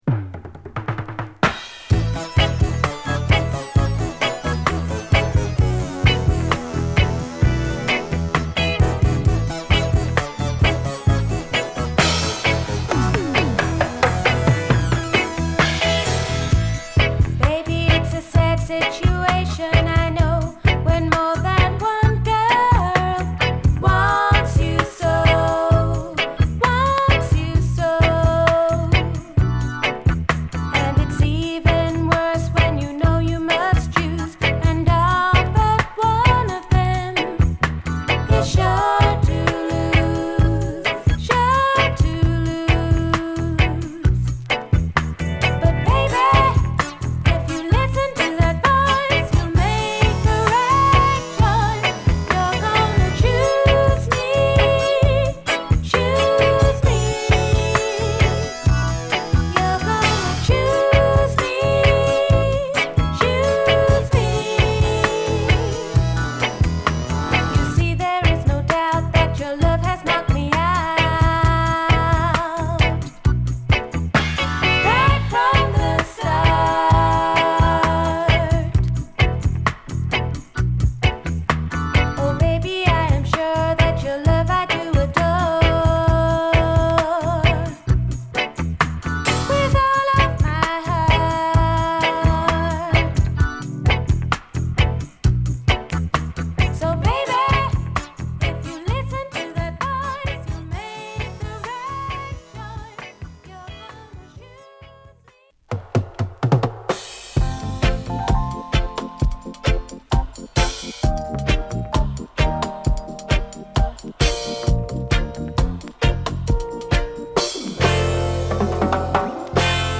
素晴らしいラバーズ・ロックを収録！